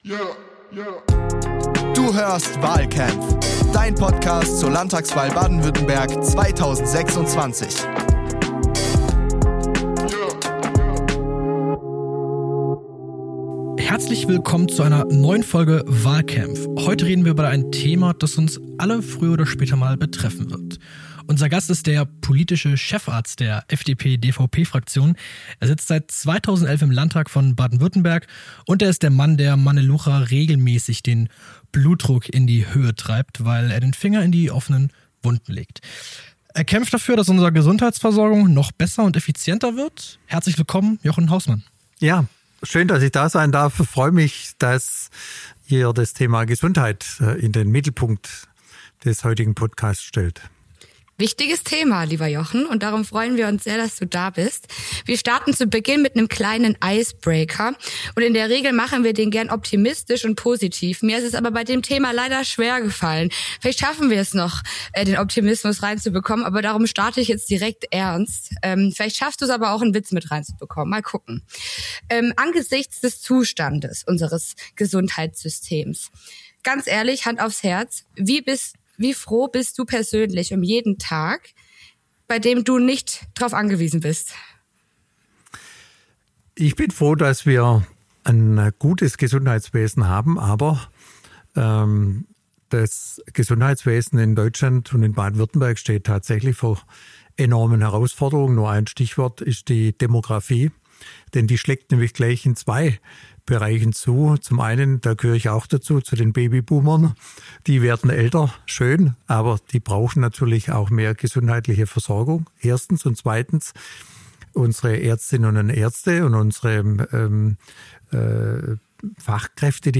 Jochen erklärt, warum wir aufhören müssen, das System kaputtzusparen, und anfangen müssen, es intelligent umzubauen. Ein Gespräch über die Angst vor Krankenhaus-Schließungen, mutige Digitalisierung und das Rezept für die nächsten fünf Jahre.